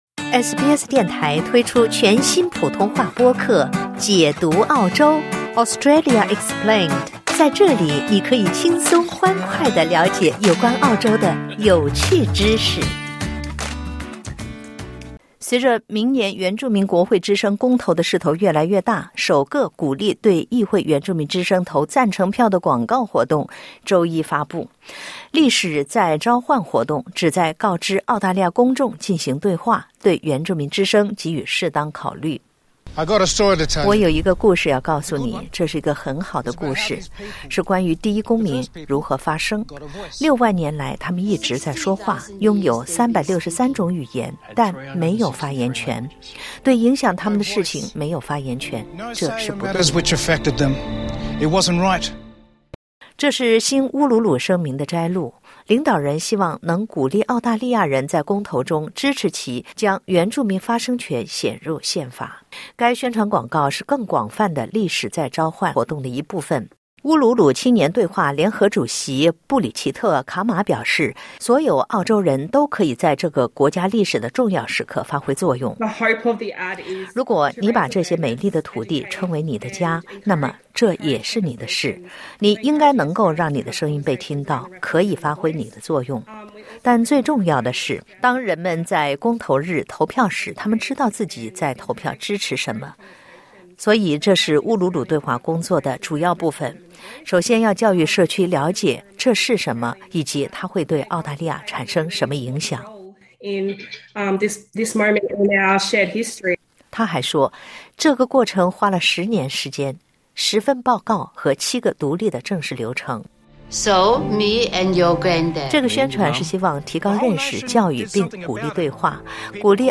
联邦总理阿尔巴尼斯在九号电台采访时说，虽然“发自内心的乌鲁鲁声明”得到了广泛支持，但并非所有原住民都支持它。(点击上图收听报道)